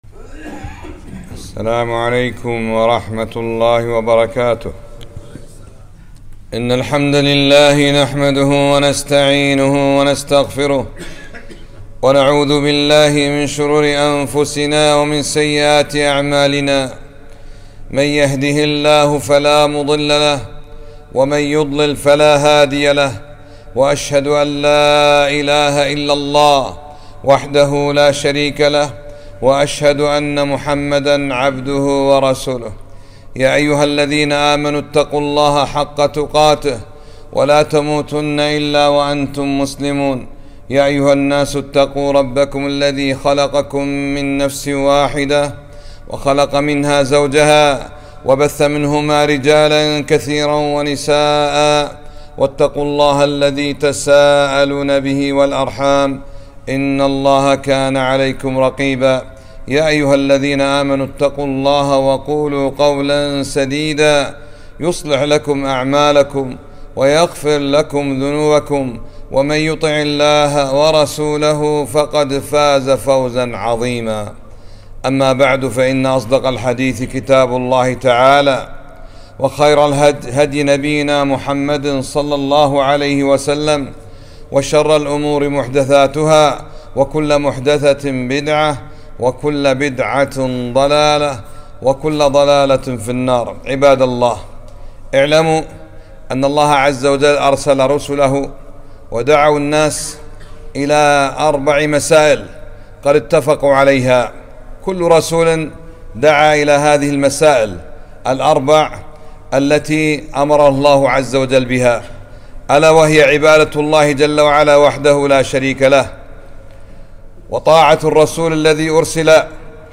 خطبة - المسائل الأربع التي اتفقت عليها جميع الرسل